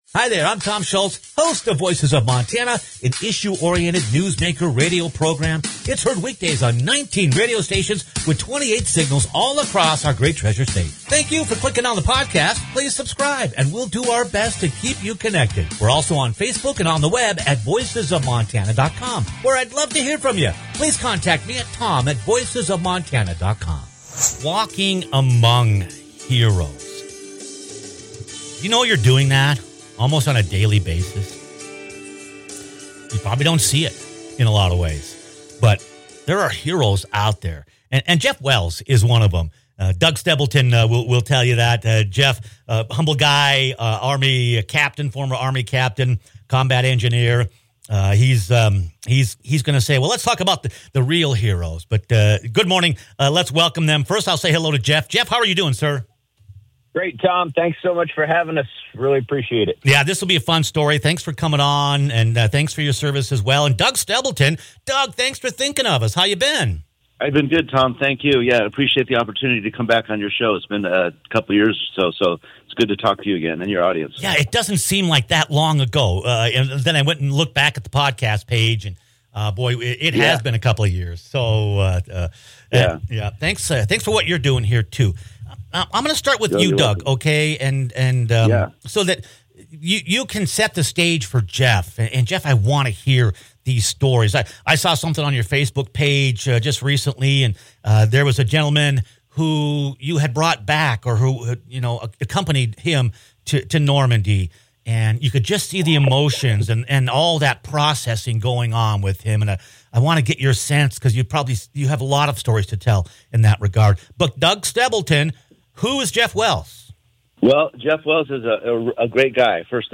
A powerful conversation